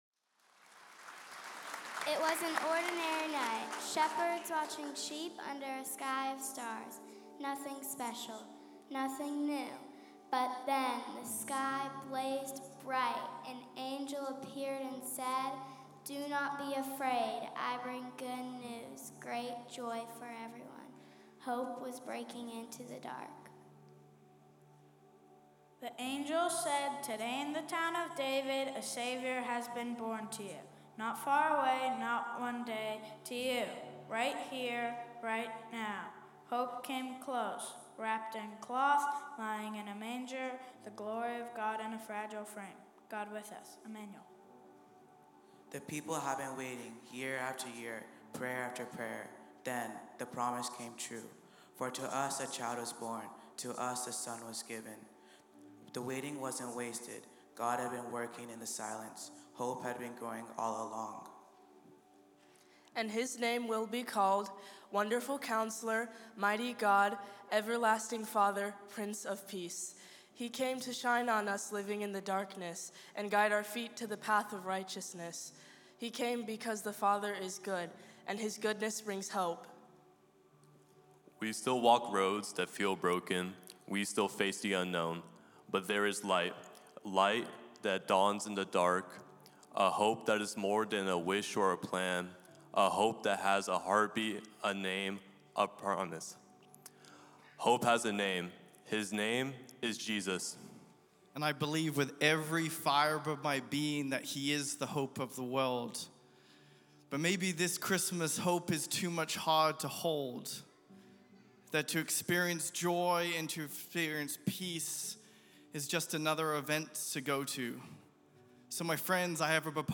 This week’s all-church family service features our Generations Team and kids choir.